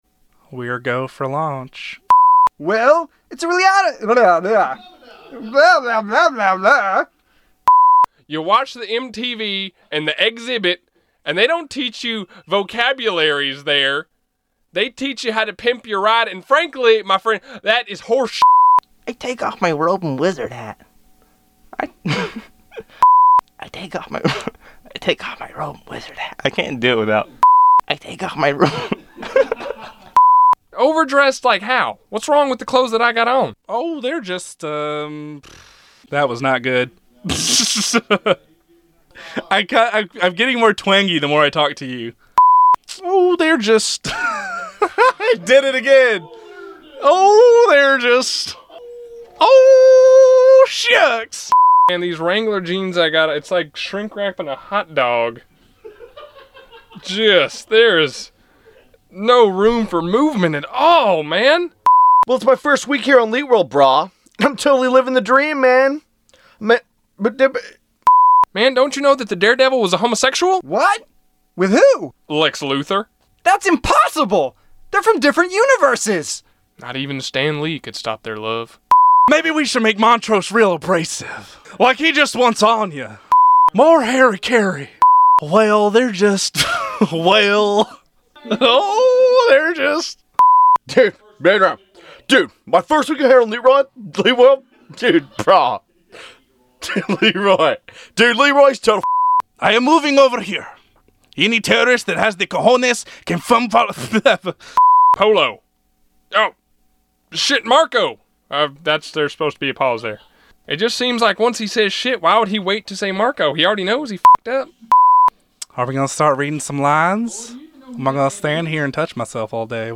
So, if it tickles your fancy (and if it tickles anything else I don’t want to know), check out our Episode One audio outtakes:
tlw_101audioouttakes.mp3